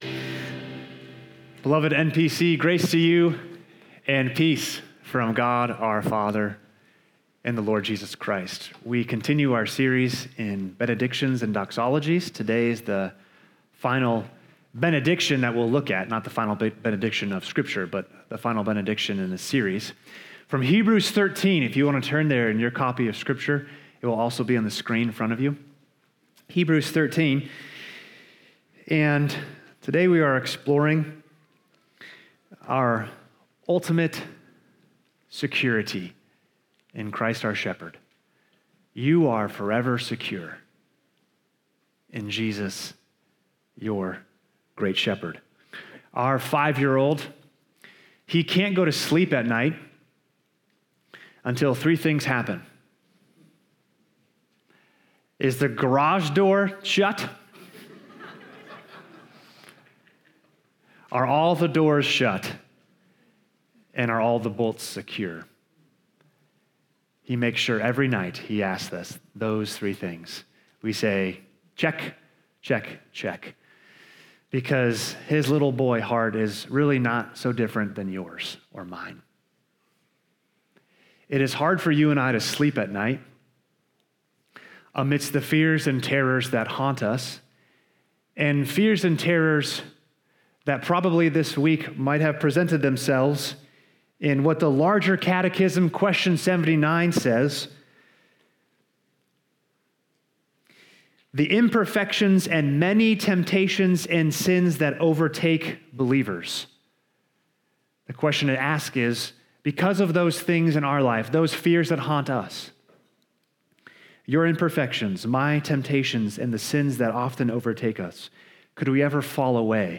Naperville Presbyterian Church Sermons Podcast - Hebrews 13:20-21 | Free Listening on Podbean App